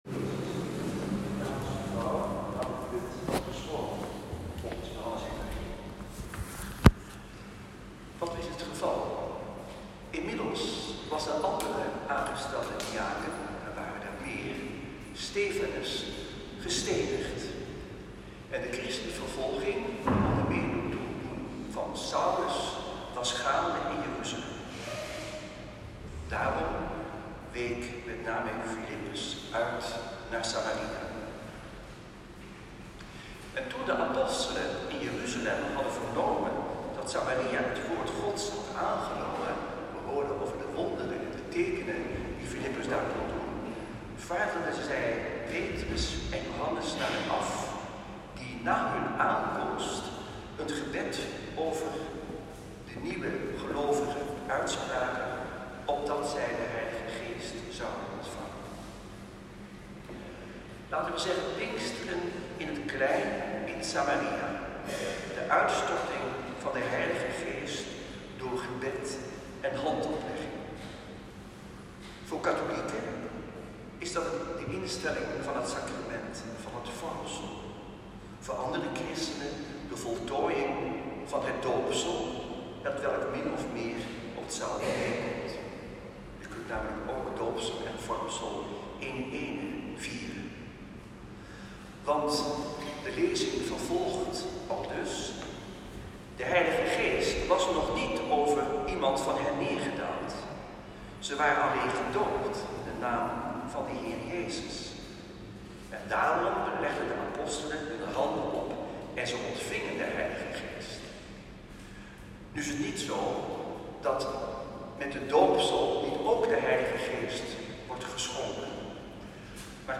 Zesde zondag van Pasen. Celebrant Antoine Bodar.
Preek-1.m4a